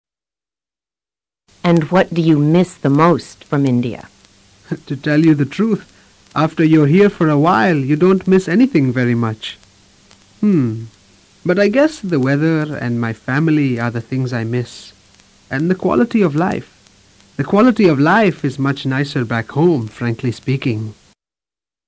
Escucha el final de la conversación anterior. Concéntrate en interpretar cuáles son las TRES cosas que Ajay más extraña de su país nativo y completa los espacios en blanco.